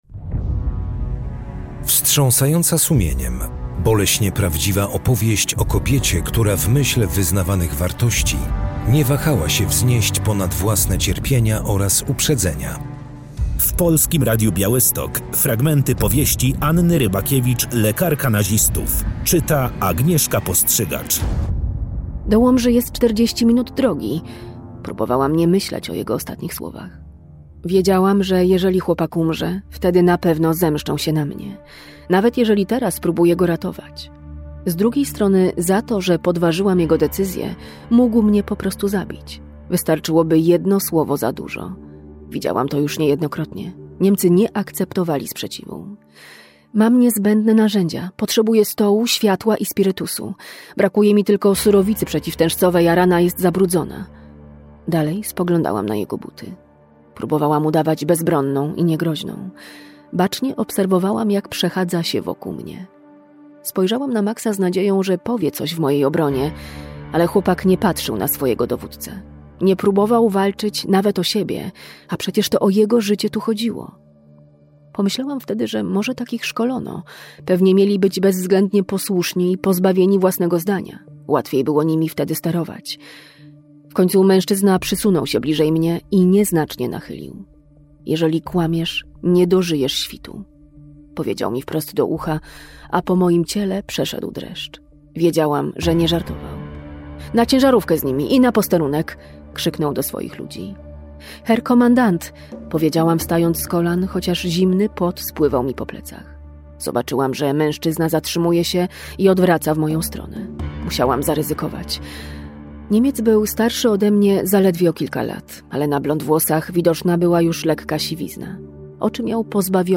POWIEŚĆ TYGODNIA